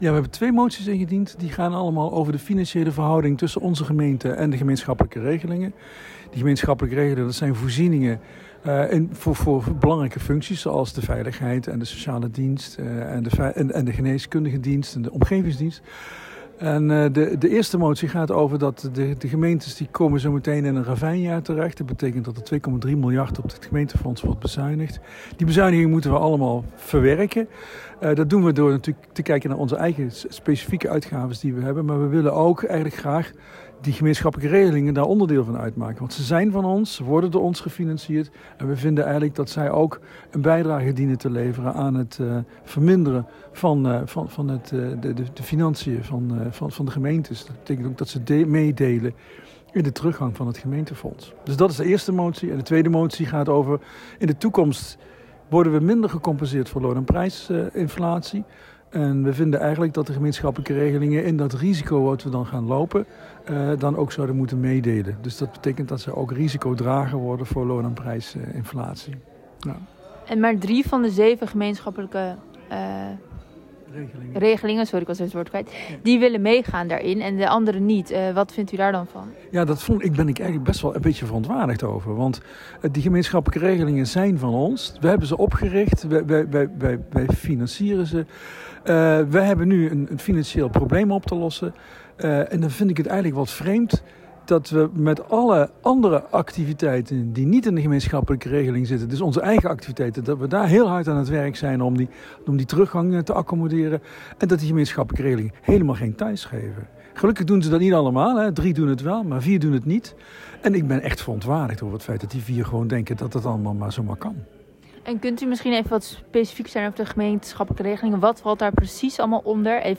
Progressief Oegstgeest-raadslid Tom Groot over de financiële zorgen voor Oegstgeest.